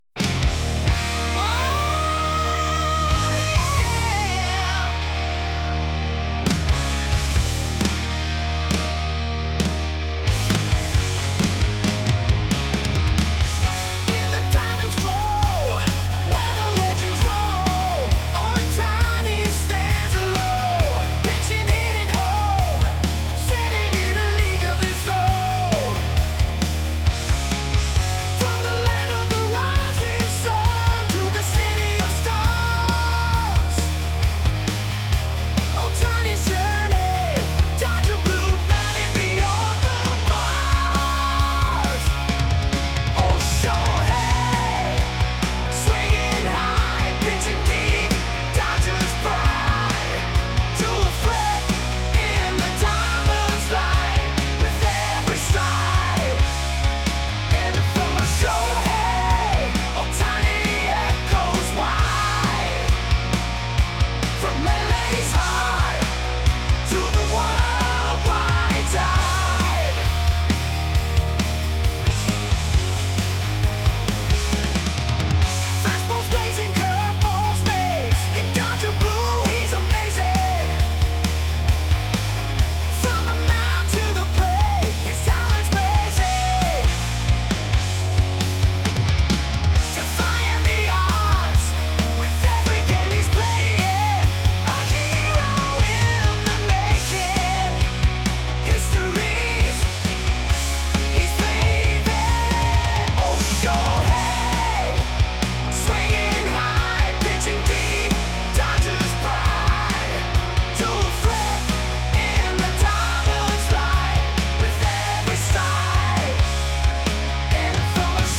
大谷翔平さんの応援ソングがAIによって作成される アメリカのハードロック風 | AI Street Snap
この曲は、のハードロックを彷彿とさせる力強いサウンドが特徴で、彼の野球に対する情熱と才能を讃える内容となっています。